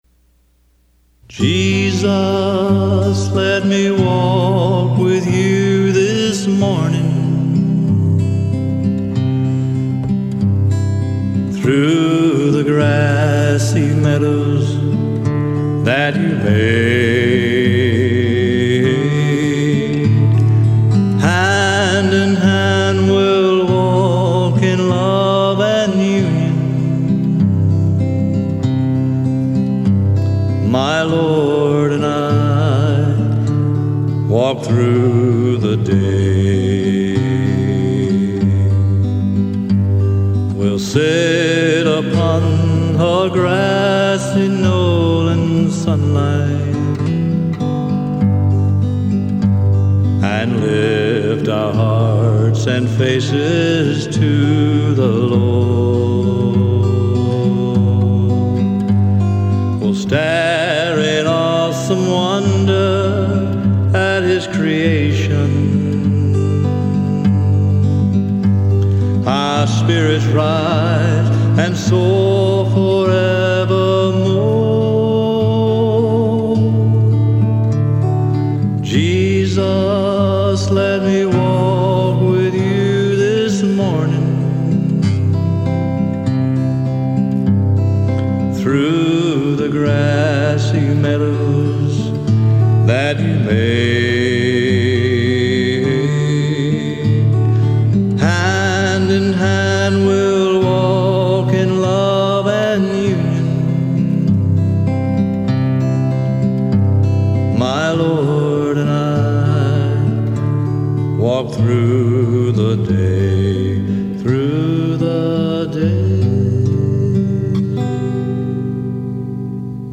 Vocalist/Guitar/Tambourine
Harmony
Bass Guitar